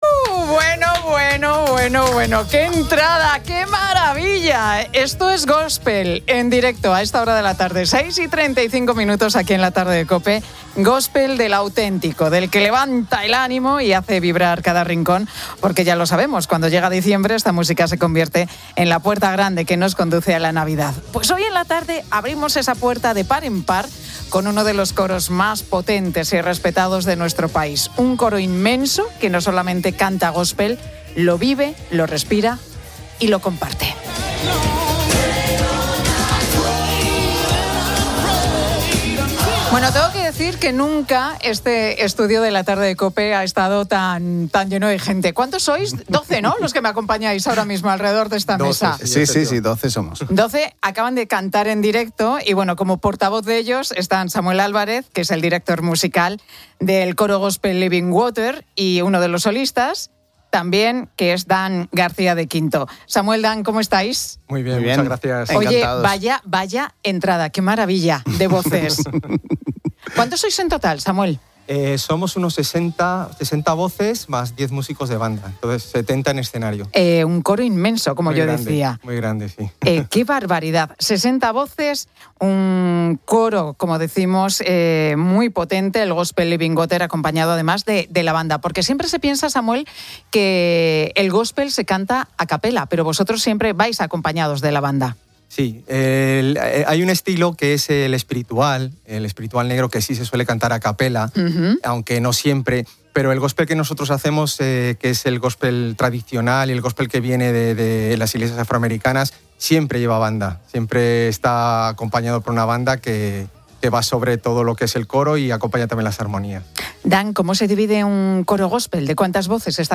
Escucha aquí la entrevista de La Tarde de Cope